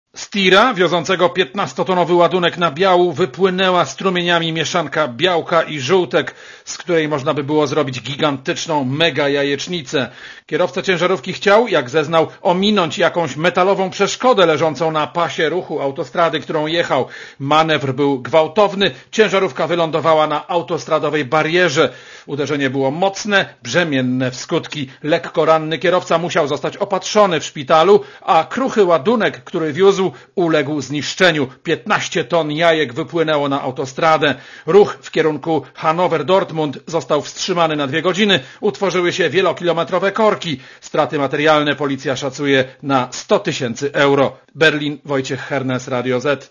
Korespondencja z Niemiec